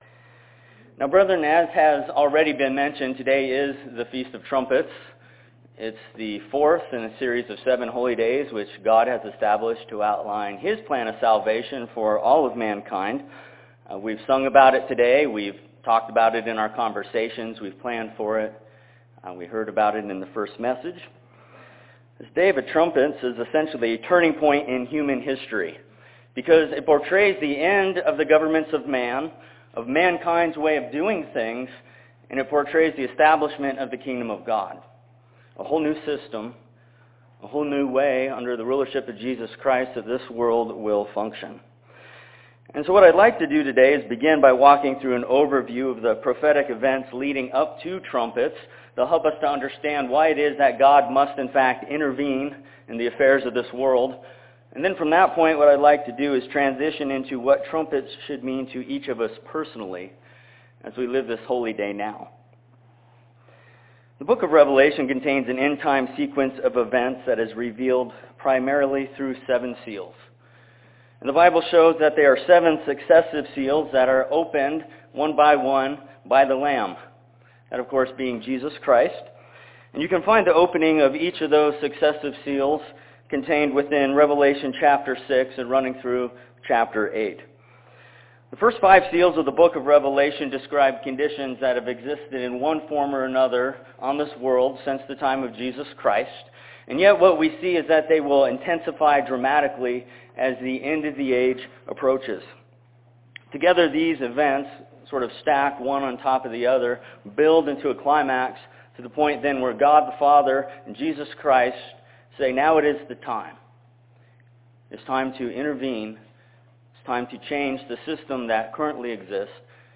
Sermon on Feast of Trumpets morning service, Thursday, in Spokane, Washington.